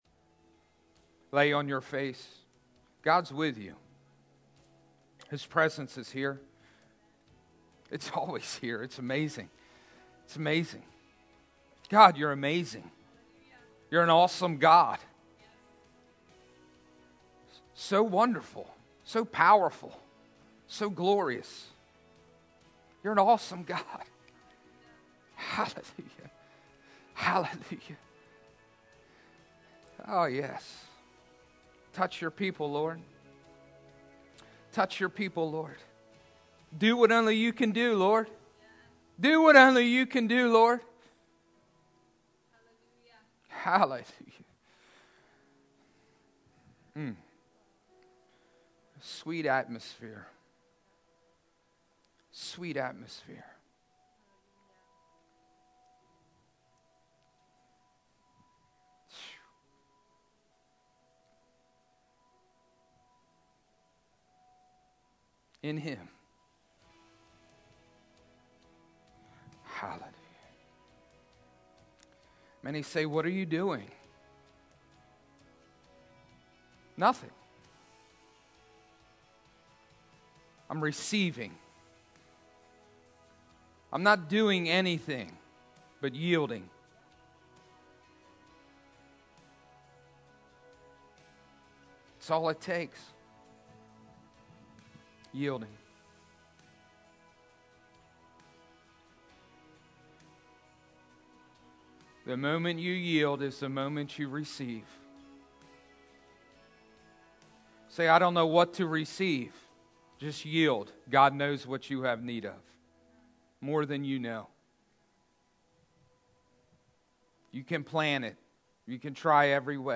Sermons | New Freedom Church